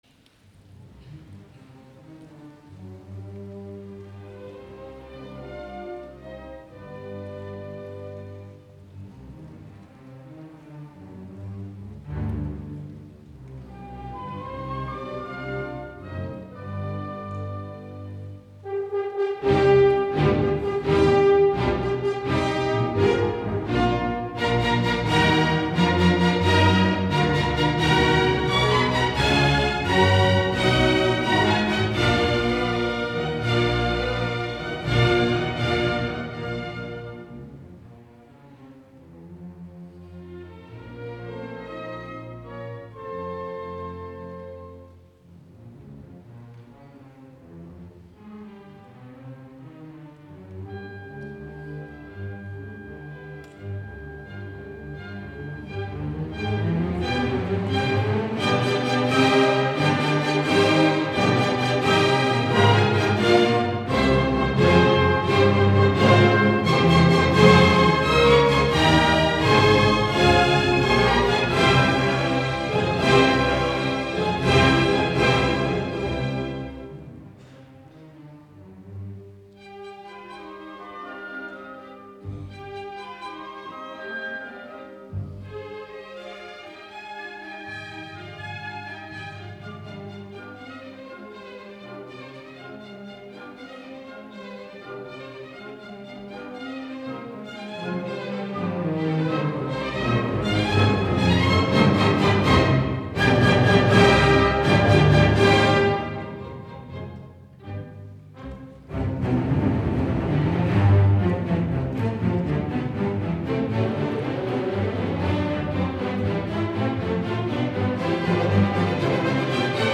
3. Allegro